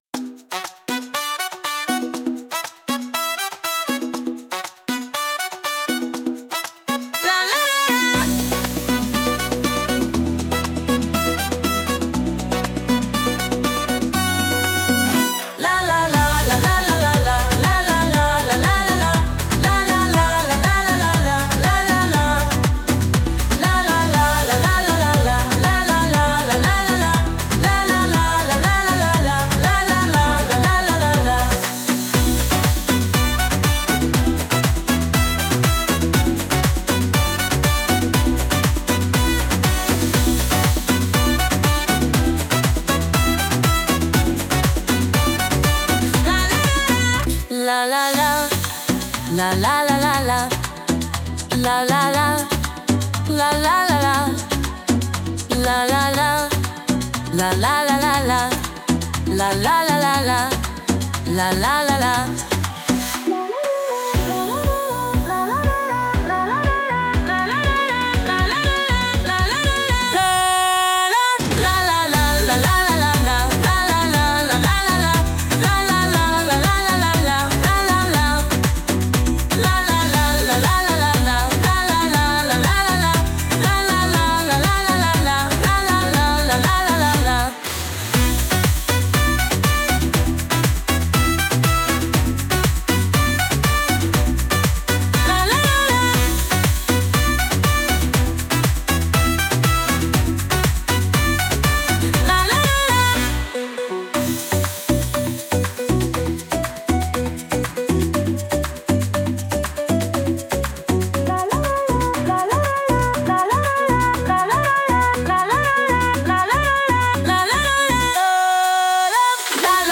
タイトル通り、「ラ・ラ・ラ」というキャッチーな歌声が耳に残る、明るく陽気なラテン・ナンバー！
ラテン特有の情熱的なノリはありつつも、リズムは非常に素直で分かりやすく作られています。
聴いているだけでウキウキするような曲調なので、とびきりの笑顔で元気いっぱいに踊りたい選手にぴったりです。